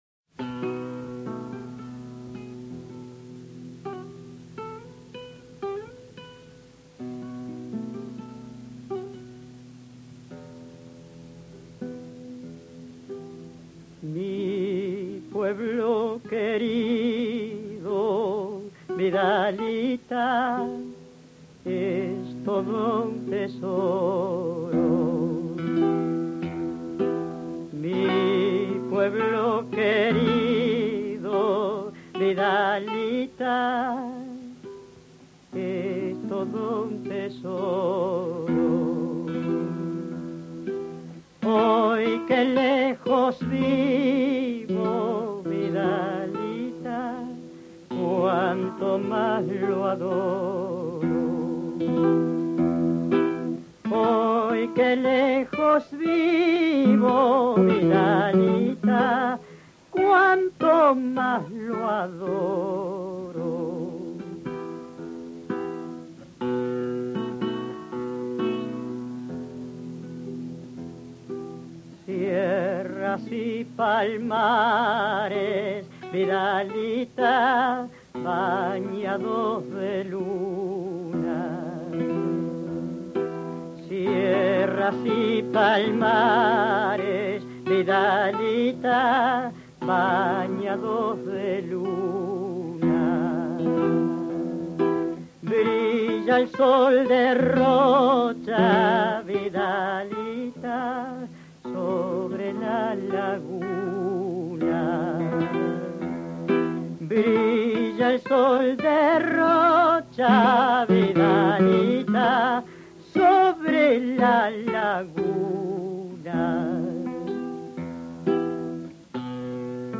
Especie del cancionero folklórico uruguayo.
guitarra
Se acompaña con guitarra y su poesía está basada en una cuarteta hexasílaba, donde al final del primer y tercer verso se le agrega la palabra Vidalita, cuyo significado es "vida mía".